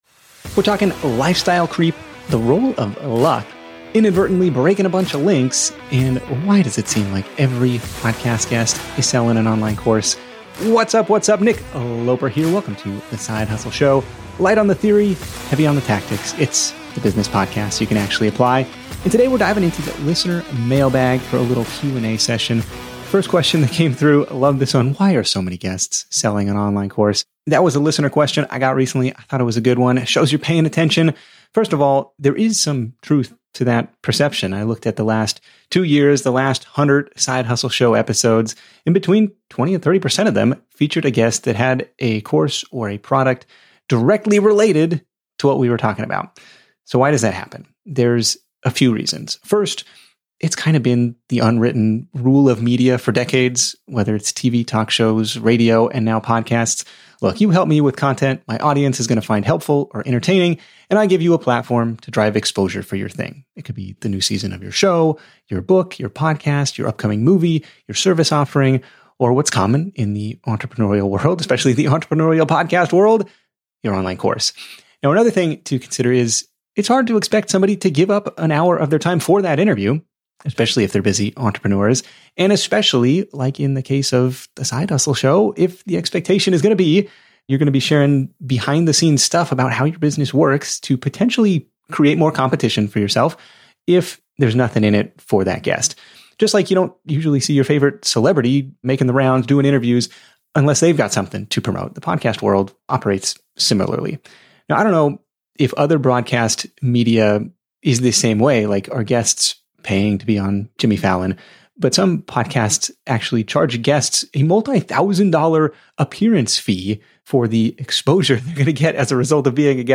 In this episode, we're diving deep into the listener mailbag for a Q&A session.